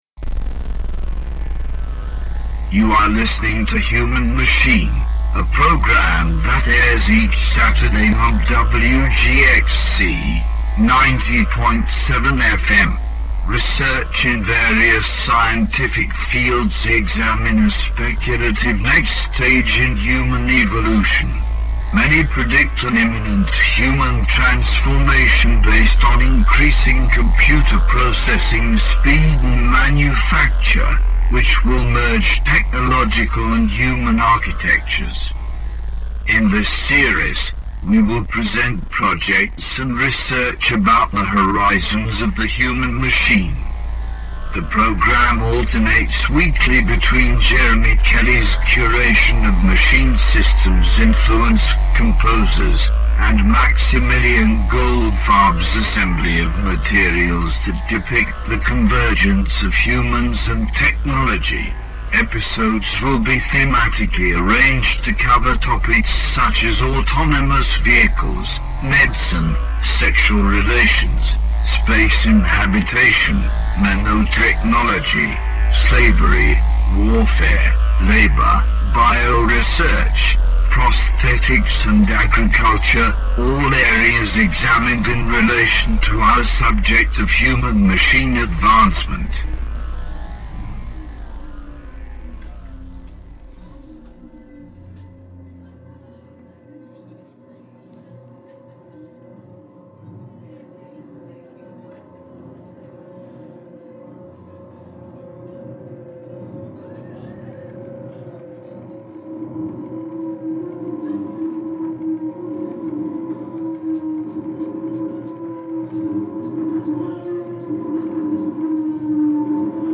Human Machine is a hour-long radio broadcast produ...